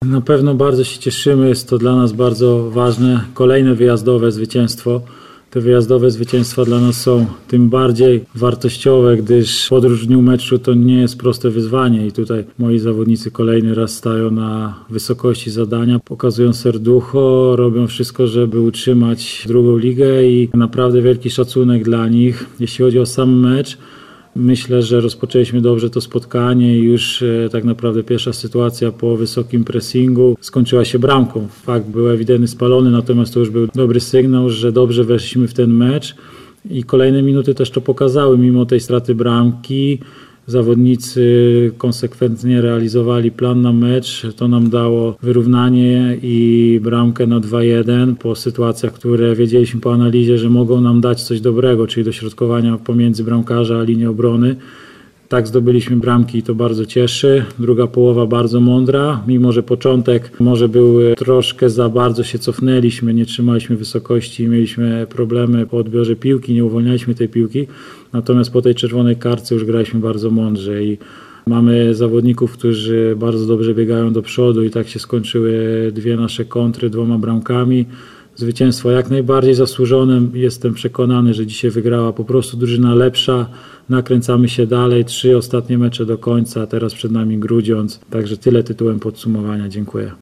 Na pomeczowej konferencji prasowej